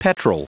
Prononciation du mot petrol en anglais (fichier audio)
Prononciation du mot : petrol